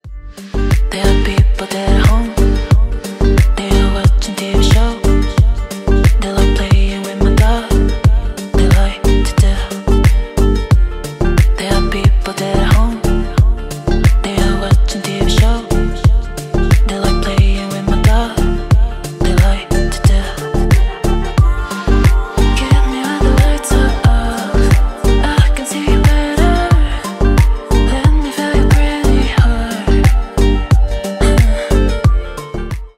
deep house
клубные , красивые , спокойные